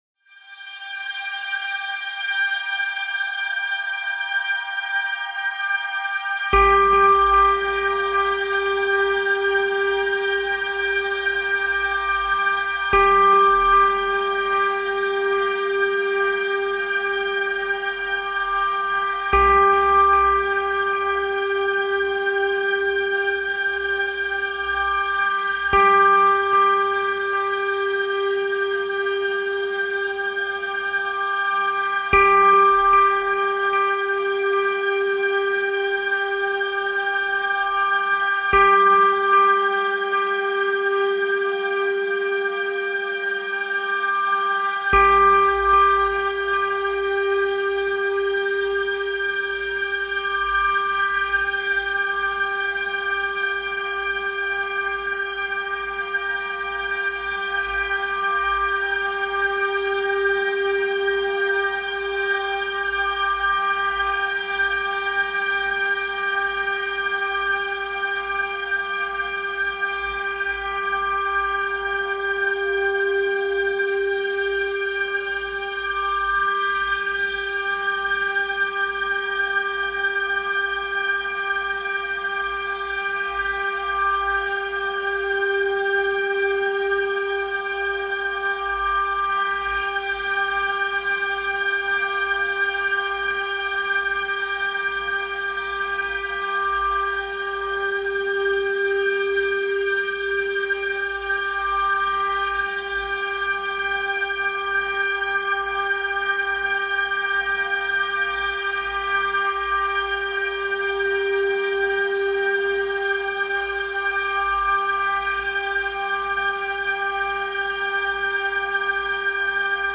396 Hz - Frequência Libertadora